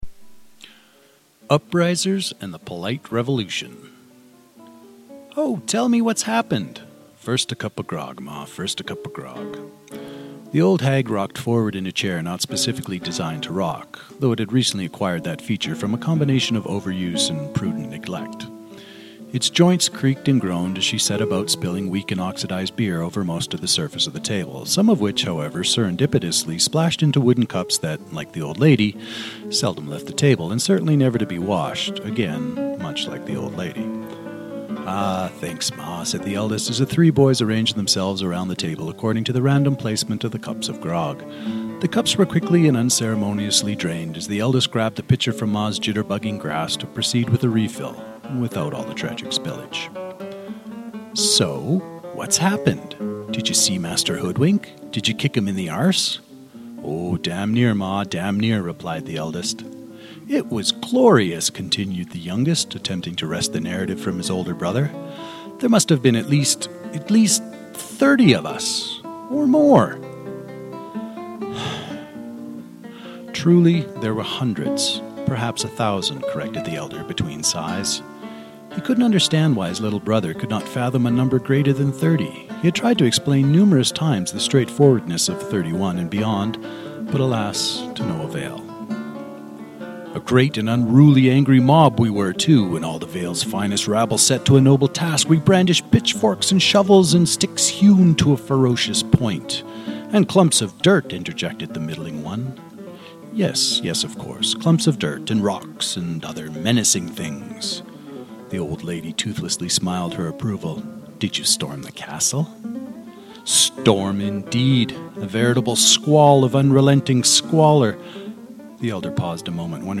Author Narration with musical accompaniment